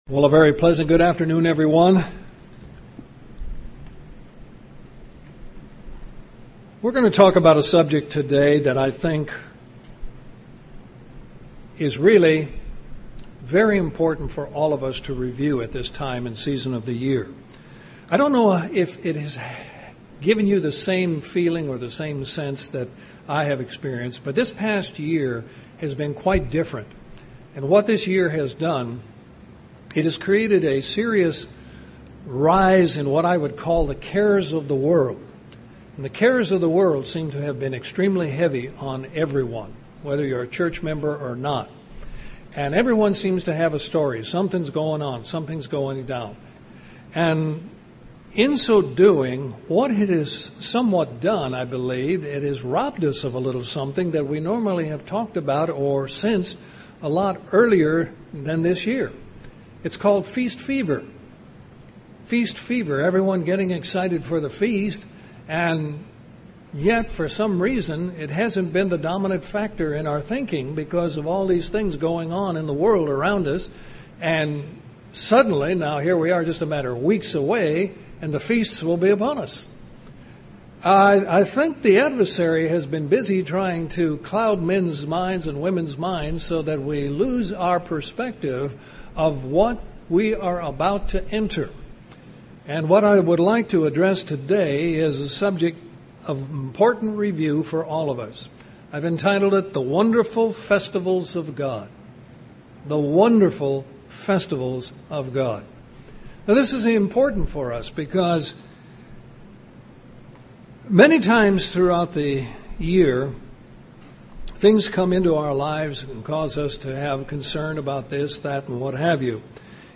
Given in Columbus, GA Central Georgia
UCG Sermon Studying the bible?